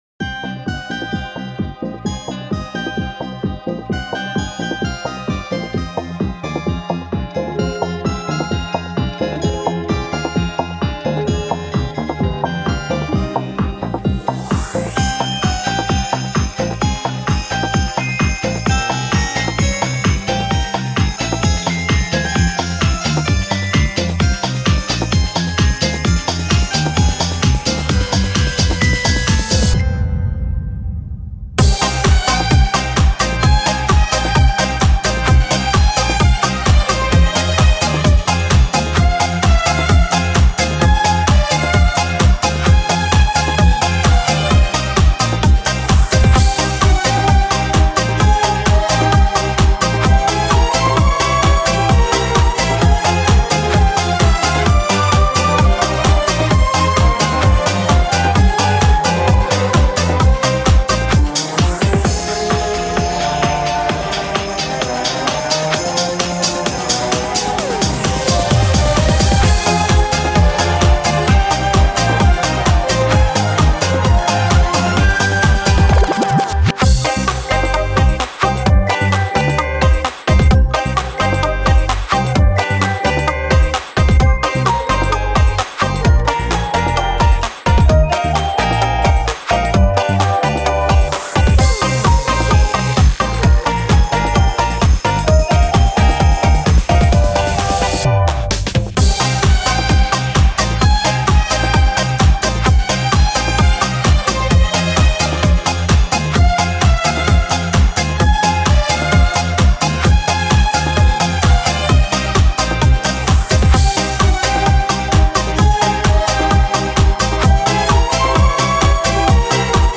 они старые мелодии переигрывают по новому.
В современной аранжировке.